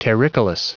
Prononciation du mot terricolous en anglais (fichier audio)
Prononciation du mot : terricolous